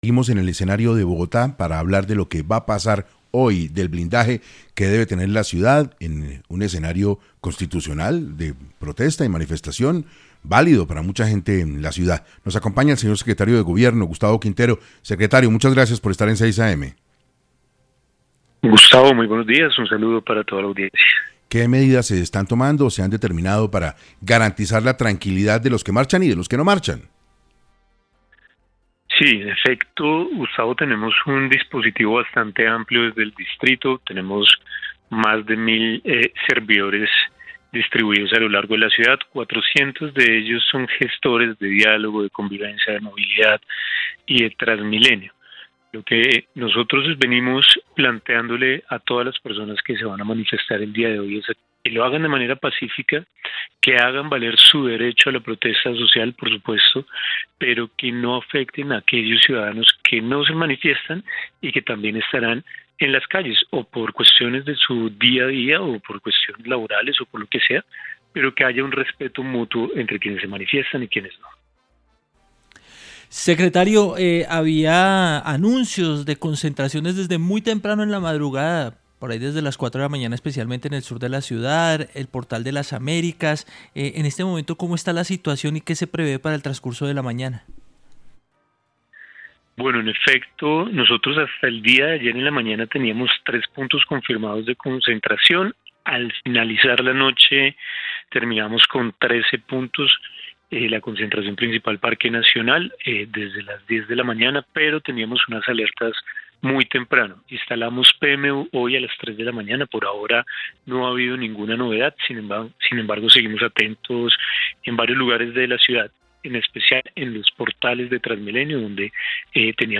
En entrevista para 6AM, el Secretario de Gobierno de Bogotá, Gustavo Quintero, detalló el plan de acción que se tiene previsto para los bogotanos.